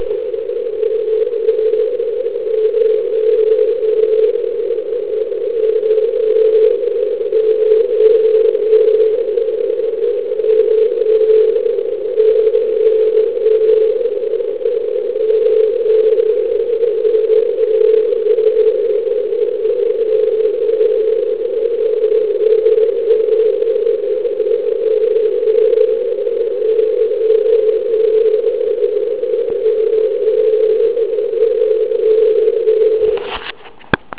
Poslouchal jsem ji na standardní zařízení IC7600 s šířkou filtru 80Hz na anténu LW42m. Ten útlum je skutečně obrovský.